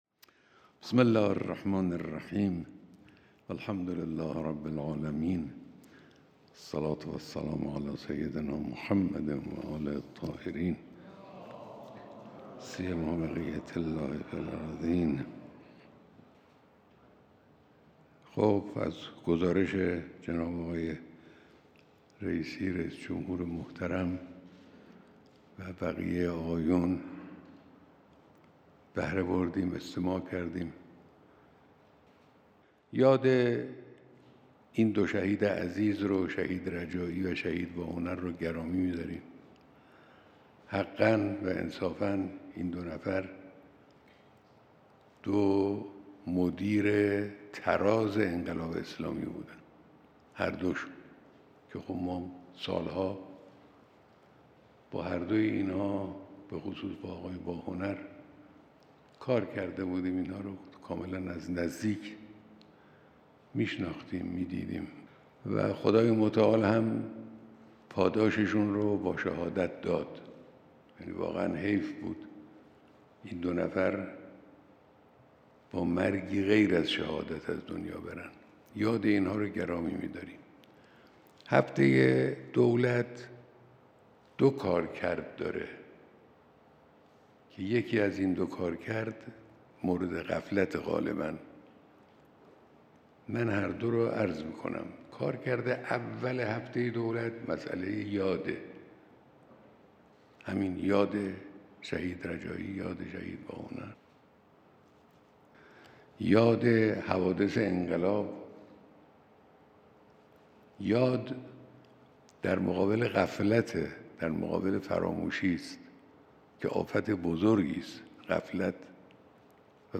بیانات در دیدار رئیس‌جمهور و اعضای هیئت دولت